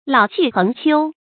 注音：ㄌㄠˇ ㄑㄧˋ ㄏㄥˊ ㄑㄧㄡ
讀音讀法：